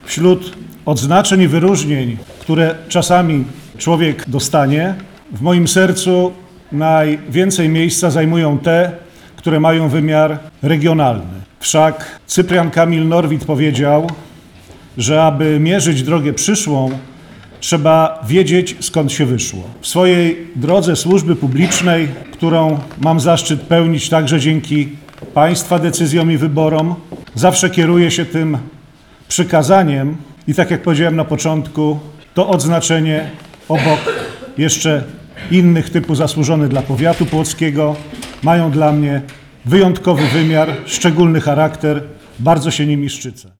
– podkreślał w swojej wypowiedzi Wicemarszałek Sejmu RP Piotr Zgorzelski.